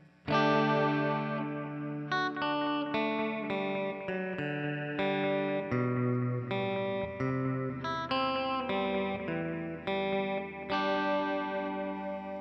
Here are a few clips BEFORE i took the pups out. Realize these were done w/ a line 6 guitar port (which I no longer have) so I wont be able to replicate this on the back end exactly, but I'll do my best!
Old Clean Clip 1